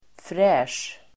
Uttal: [frä:sj]